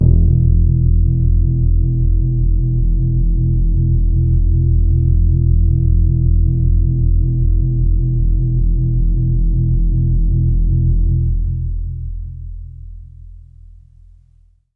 描述：这是我的Q Rack硬件合成器的一个样本。
低通滤波器使声音变得圆润而柔和。
在较高的区域，声音变得非常柔和，在归一化之后，一些噪音变得很明显。
Tag: 低音 电子 醇厚 多样品 柔软 合成器 华尔